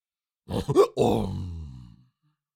Cartoon Lion, Voice, Hiccup 4 Sound Effect Download | Gfx Sounds
Cartoon-lion-voice-hiccup-4.mp3